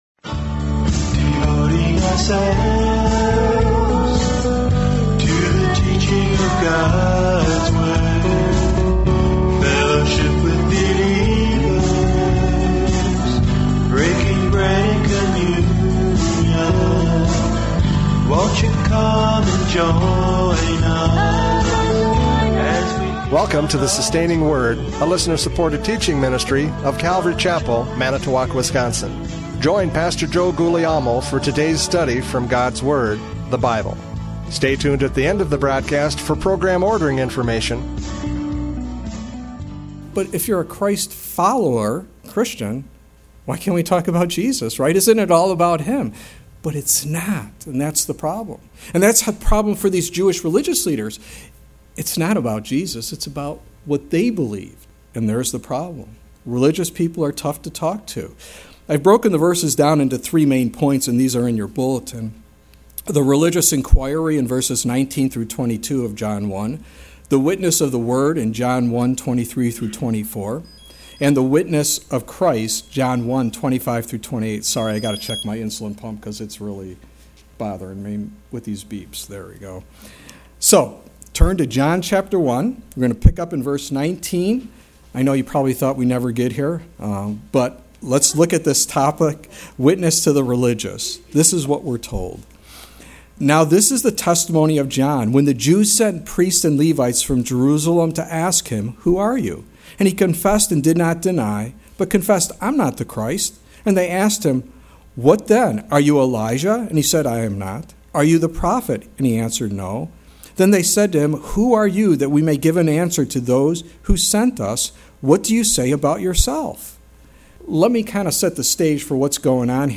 John 1:19-28 Service Type: Radio Programs « John 1:19-28 Witness to the Religious!